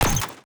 UIClick_Menu Strong Metal Rustle 05.wav